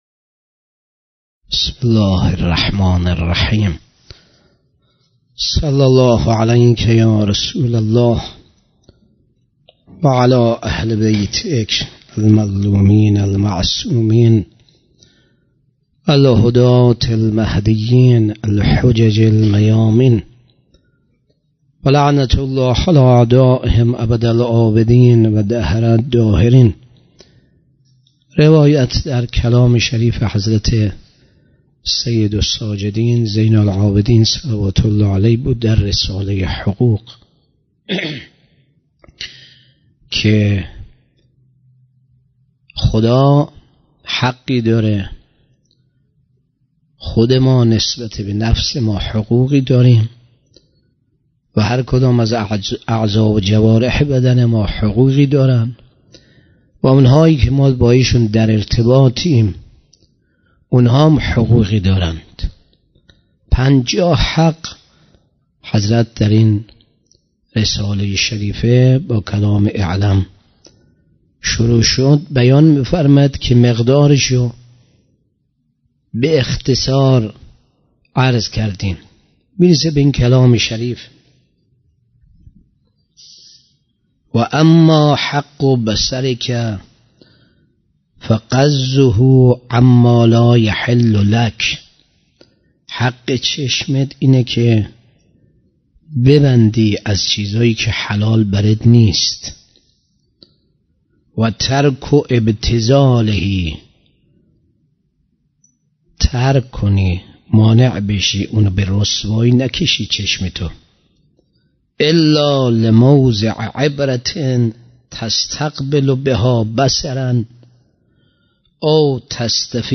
شب سوم رمضان 96 - هیئت ام البیها - سخنرانی